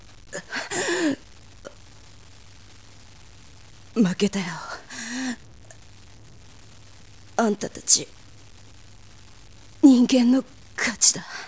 제가 전문가가 아니라서 추출한 음성파일 음질이 좀 미흡하네요.